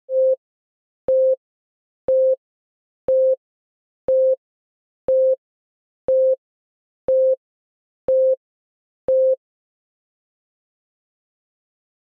Steady Beeping